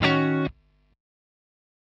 Ebm7_10.wav